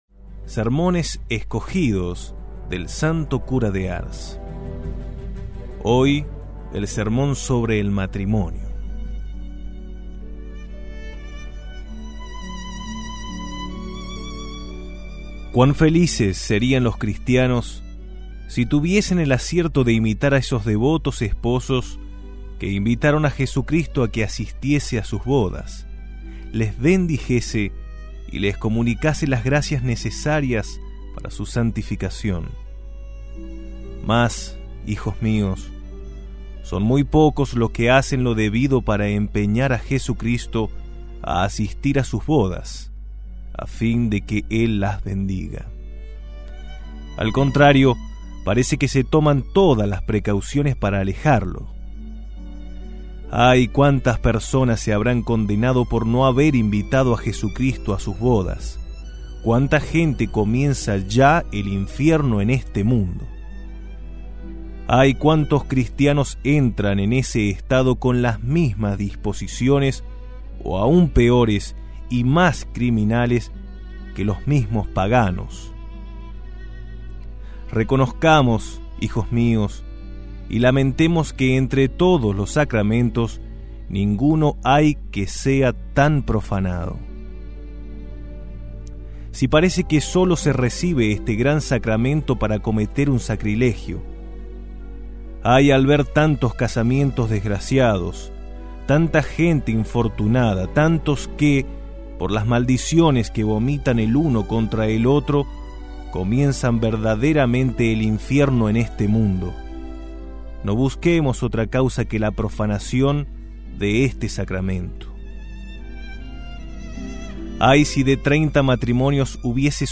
Audio–libros
Sermon-del-Santo-Cura-de-Ars-El-Matrimonio.mp3